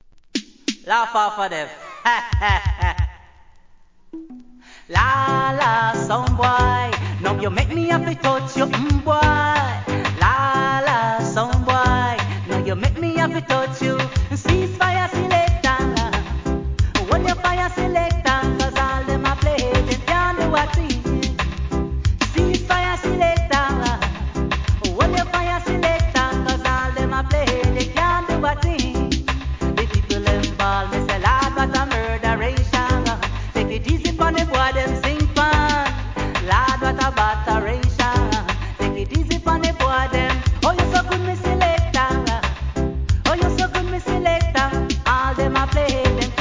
REGGAE
のフレーズ＆メロディーが無機質なオケにハマったBIGチュ〜ン!!